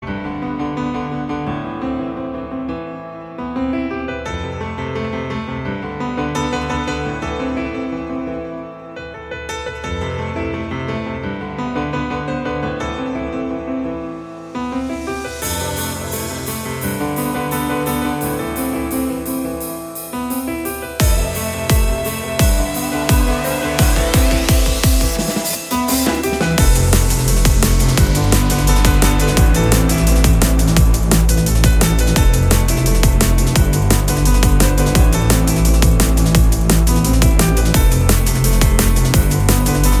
ремиксы , без слов , клубные
драм энд бейс